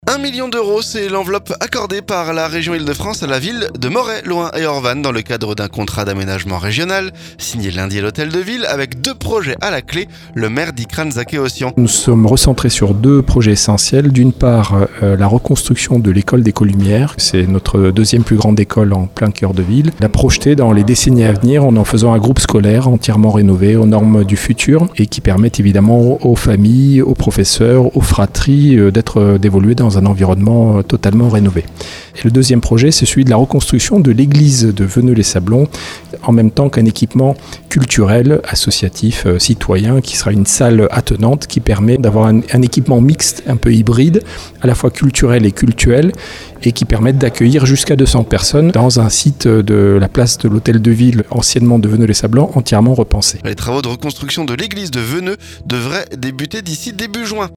Le maire Dikran Zakeossian.